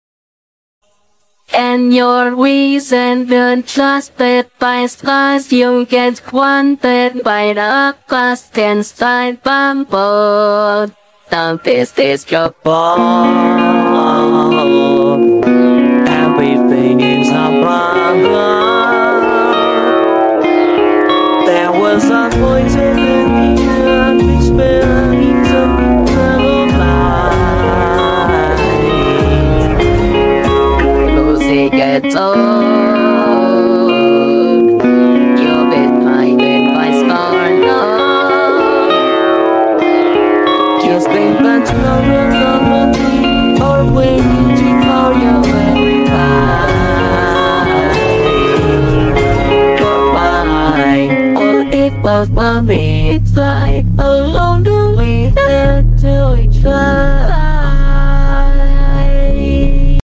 Cover IA
cover